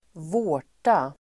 Ladda ner uttalet
Uttal: [²v'å:r_ta]